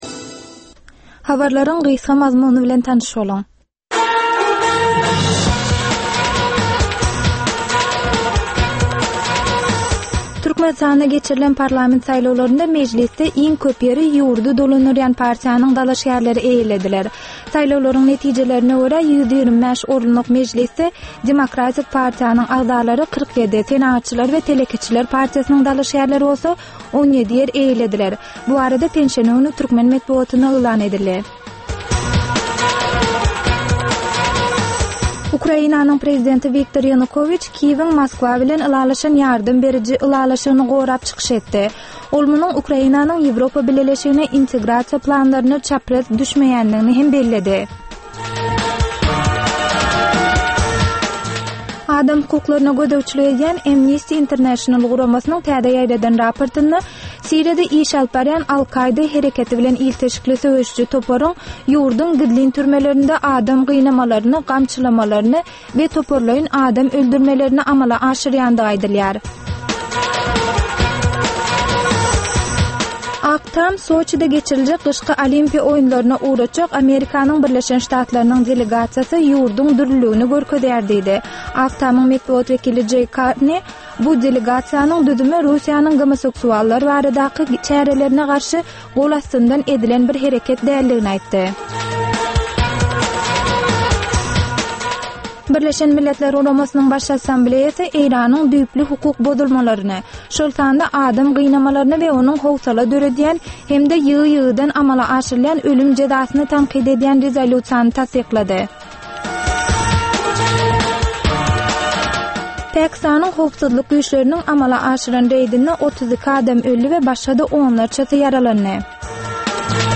"Tegelek Stol" söhbetdeşligi
Türkmenistanly synçylaryň gatnaşmagynda, ýurduň we halkyň durmuşyndaky iň möhüm meseleler barada töwerekleýin gürrüň edilýän programma. Bu programmada synçylar öz pikir-garaýyşlaryny aýdyp, jedelleşip bilýärler.